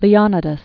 (lē-ŏnĭ-dəs) Died 480 BC.